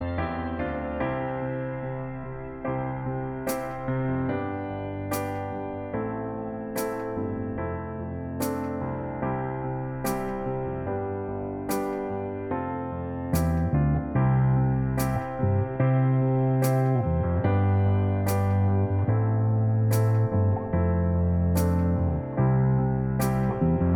Minus Lead Guitar Pop (1960s) 3:40 Buy £1.50